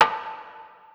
EN - Metro (Perc).wav